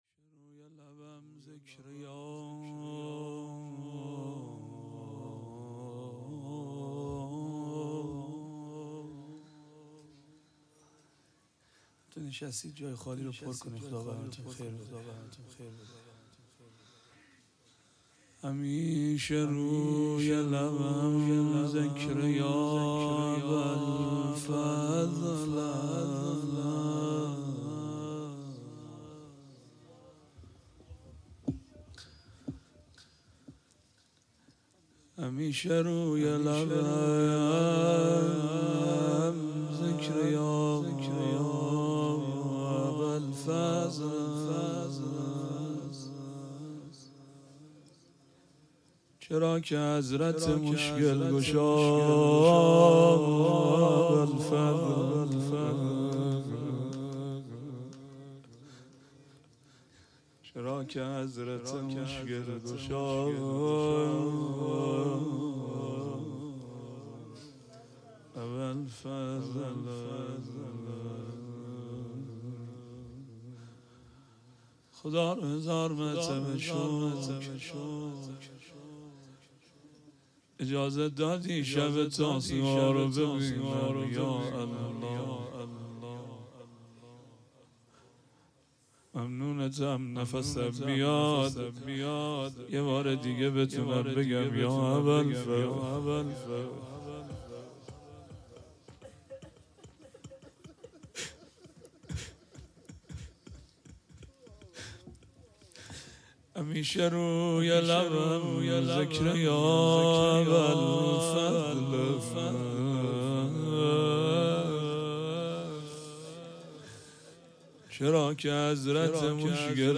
شب تاسوعا ، روضه